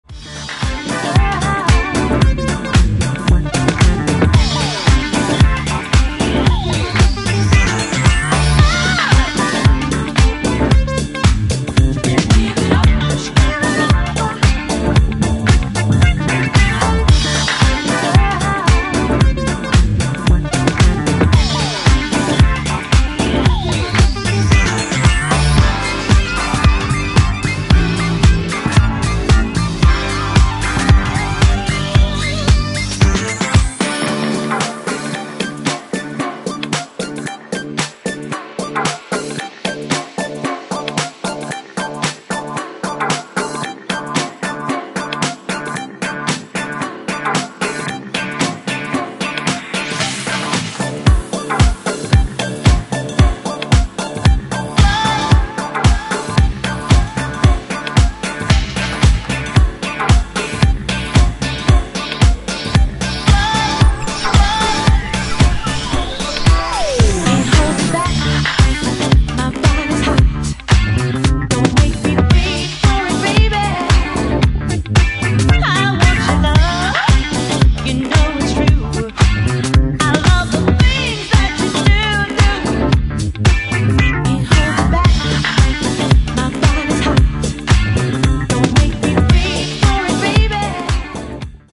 ブギーなディスコ・ハウスで盛り上がり間違いありません！！
ジャンル(スタイル) DISCO HOUSE / RE-EDIT